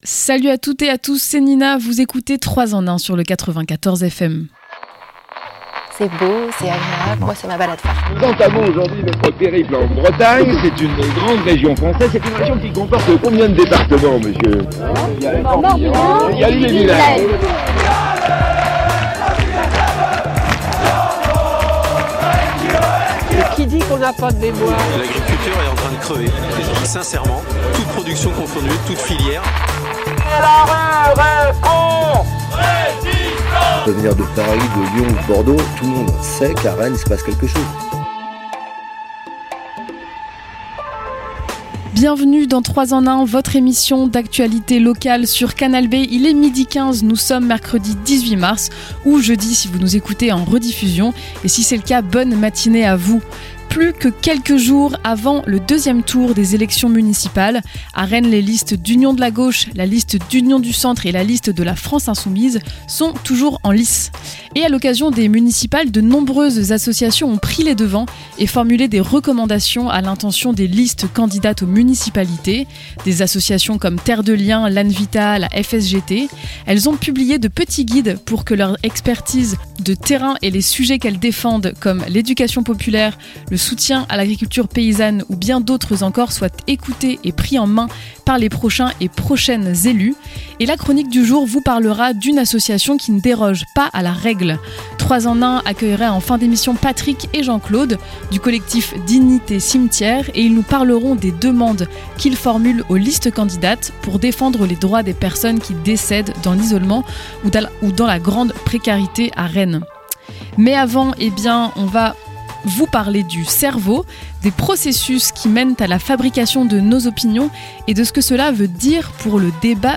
Il existe différents processus psychologiques qui amènent à la fabrication d’une opinion et la différence de perception des informations a un impact majeur sur notre société et nos manières de débattre. Dans cette interview